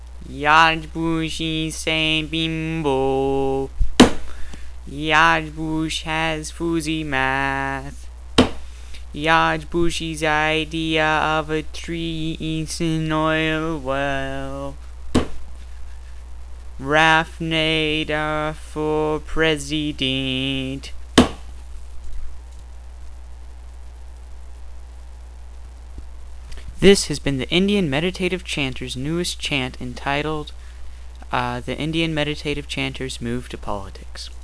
This is our newest chant involving George Bush (Shrub).